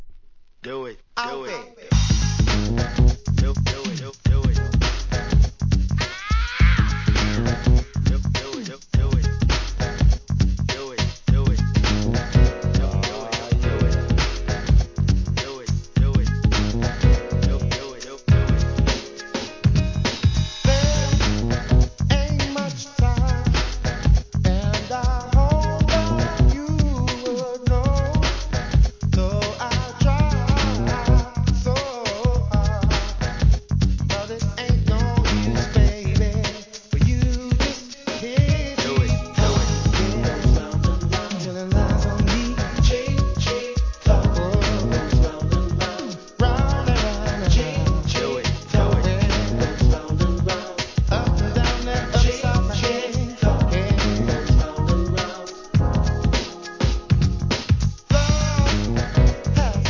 HIP HOP/R&B
洒落オツなグランドビート・クラシック!